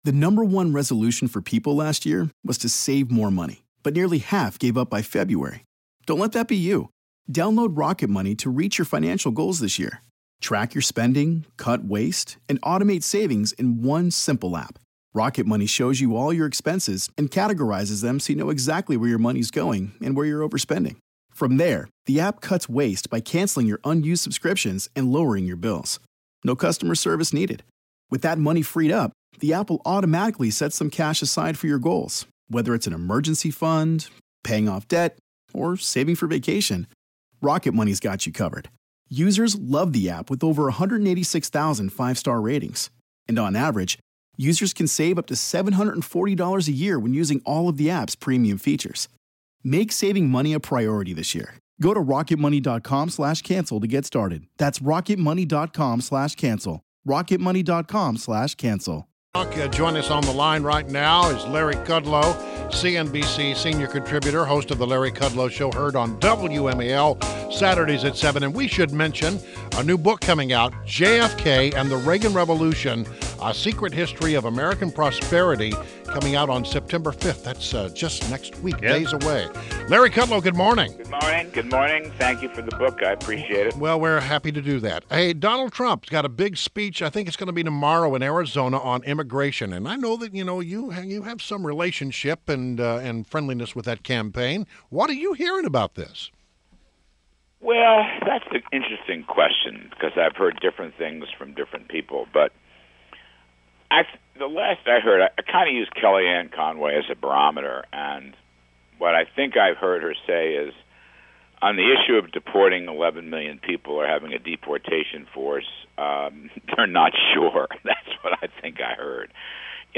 WMAL Interview - LARRY KUDLOW - 08.30.16